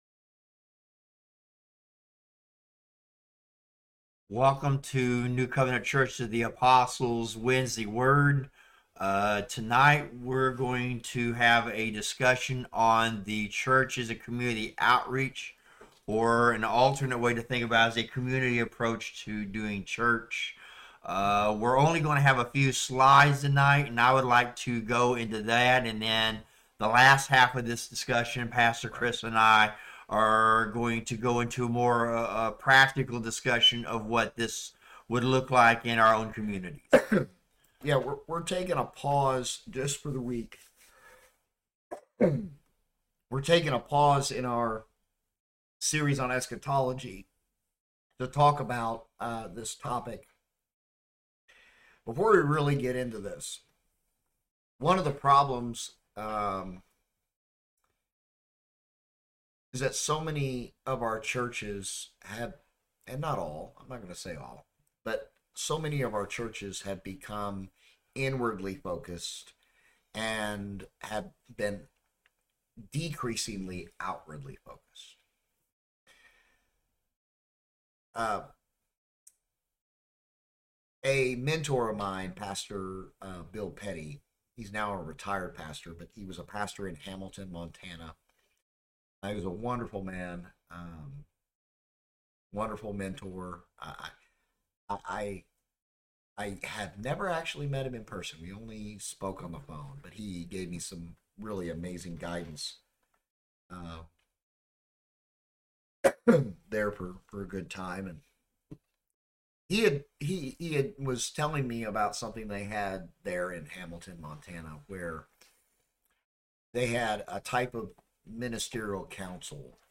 Wednesday Word Bible Study